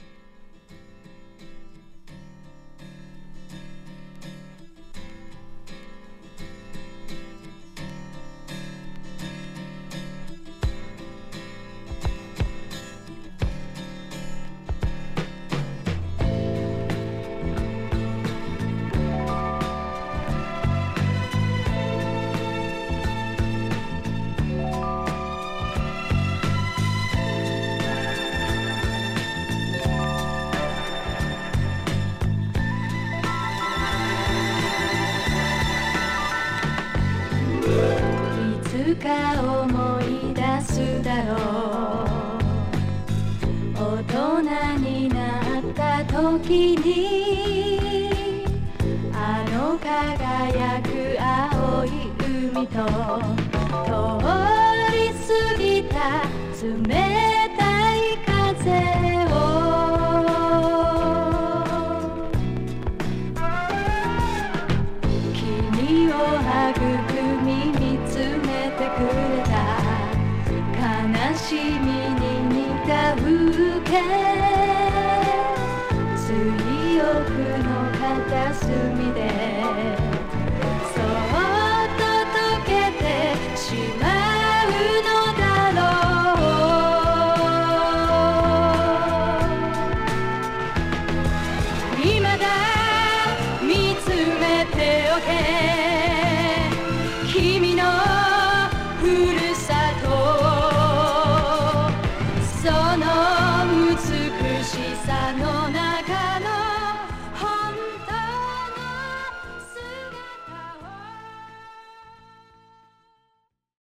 LIGHT MELLOWなジャパニーズ・シティ・ポップ名曲